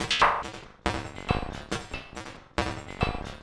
tx_perc_140_mechanism2.wav